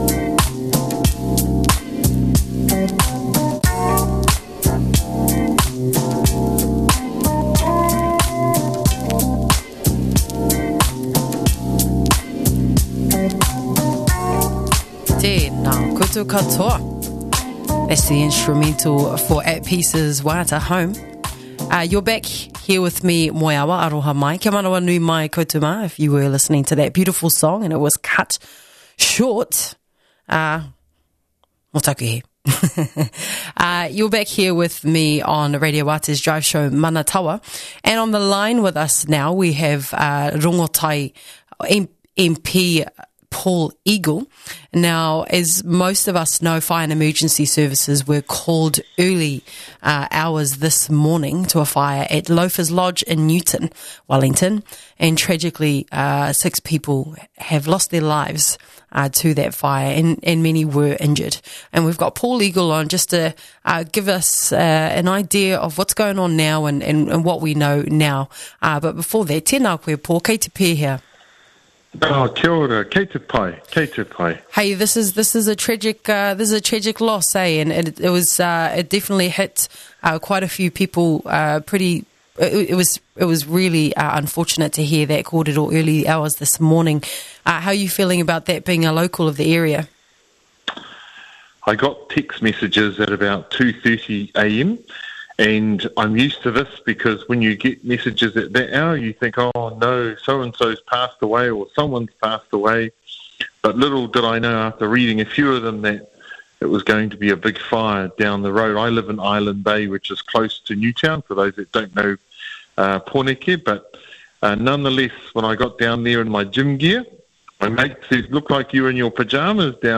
After fires at the Loafers Lodge that took the lives of what we know to be six people early this morning in Newton, Pōneke this morning, we spoke with Rongotai MP Paul Eagle to get an update.